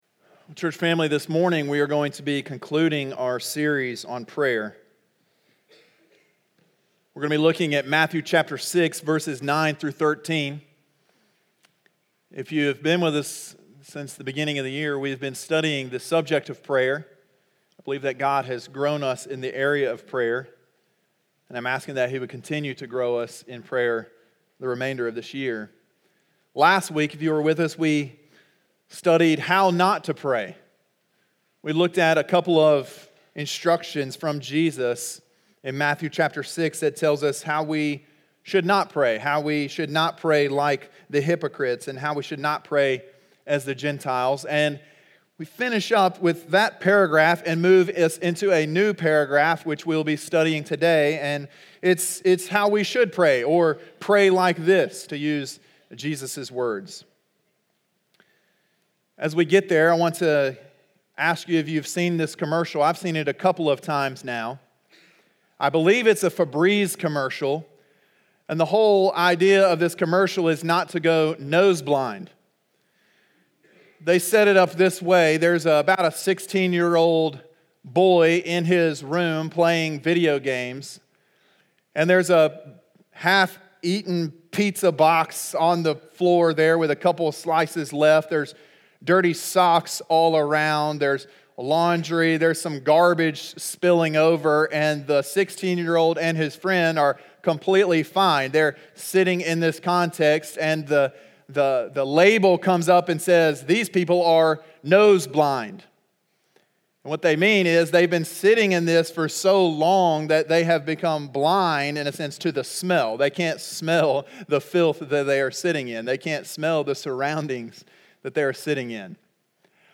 sermon2.2.20.mp3